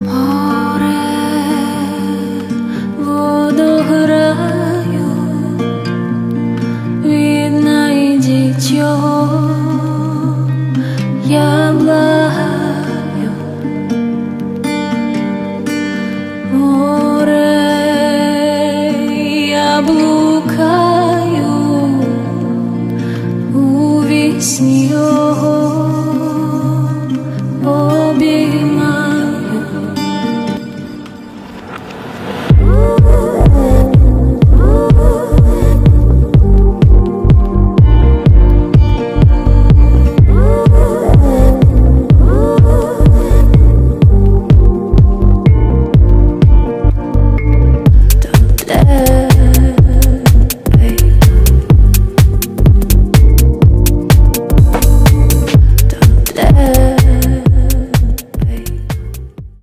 • Качество: 320, Stereo
deep house
dance
спокойные
нарастающие
красивый женский вокал
нежный голос